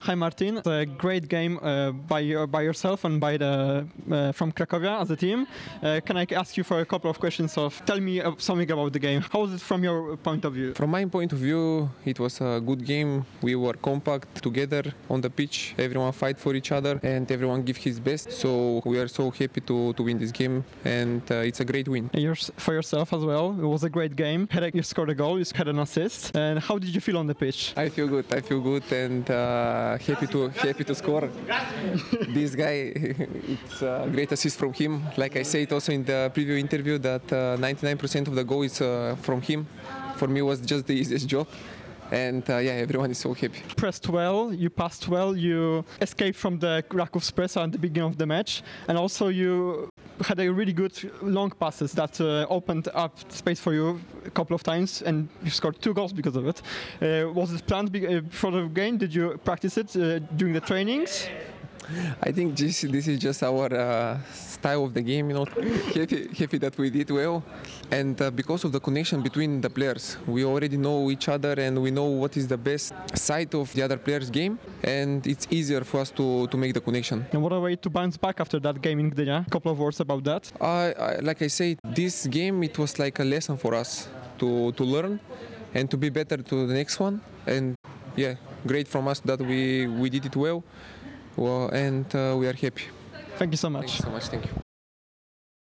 Wywiad